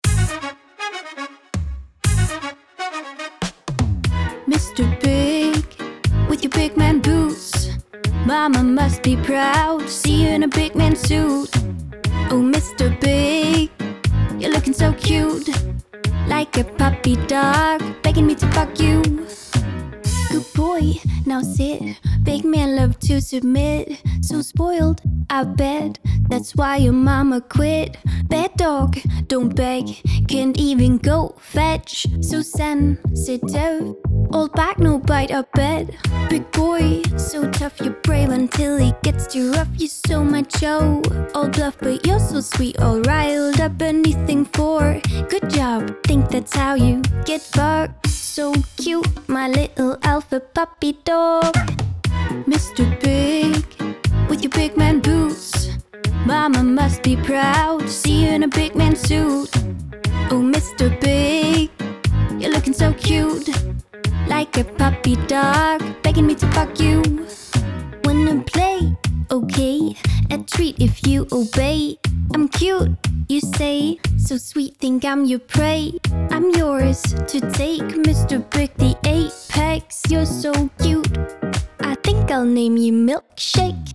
• Pop
Vokal
Band
Original pop.